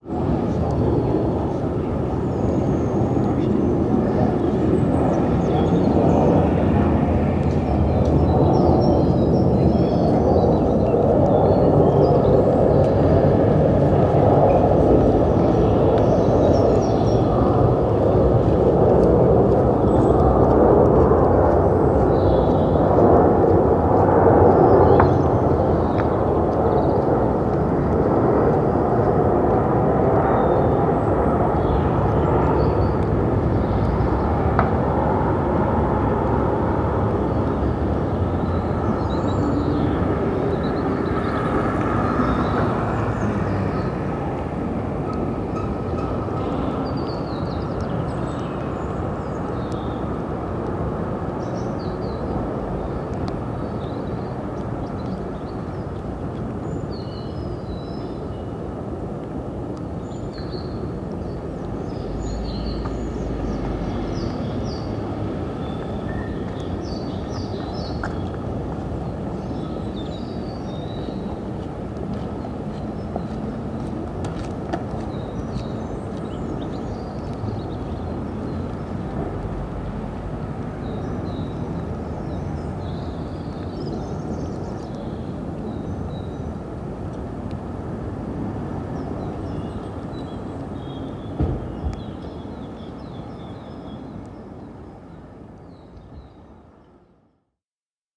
Text read in performance interwoven with audio recordings
When I heard one it sounded as if it were rising, accelerating, wanting to leave the earth's atmosphere.
Aeroplane
boundaryAeroplane.mp3